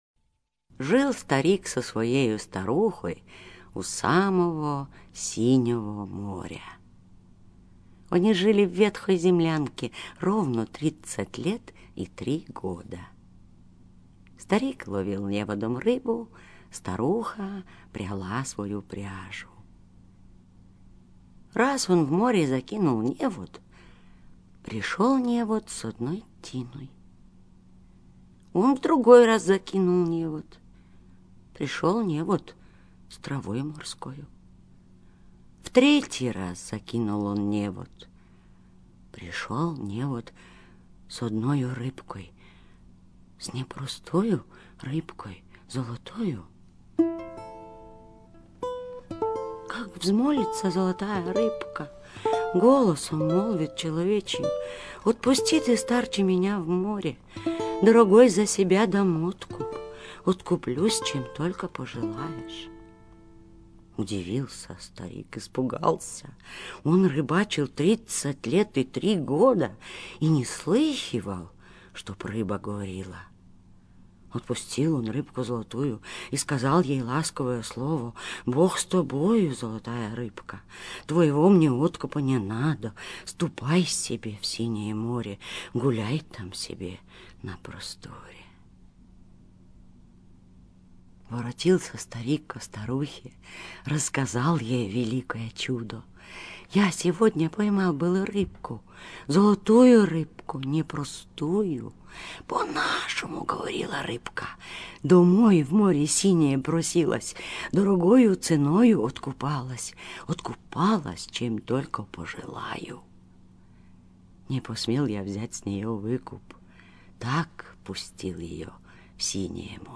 Аудиосказка о рыбаке и Рыбке: слушать онлайн